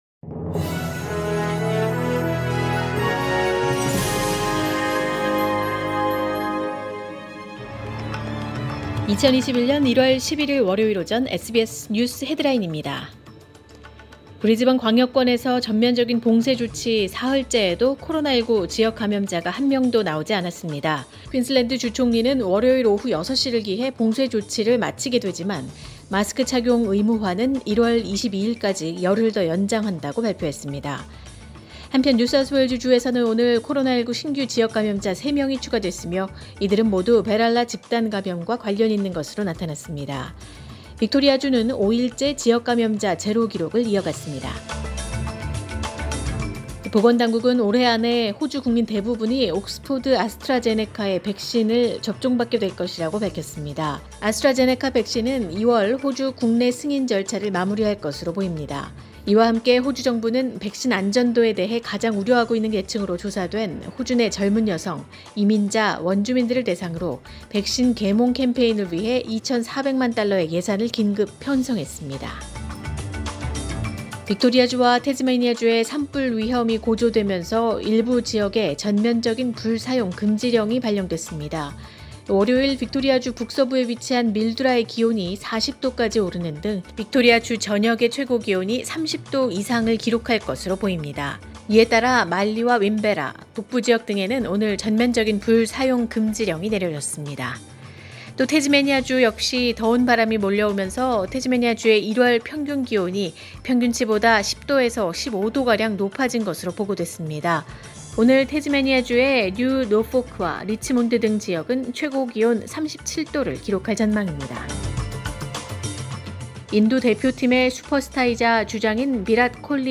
2021년 1월 11일 월요일 오전의 SBS 뉴스 헤드라인입니다.